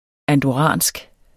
Udtale [ andoˈʁɑˀnsg ] eller [ andɒˈʁɑˀnsg ]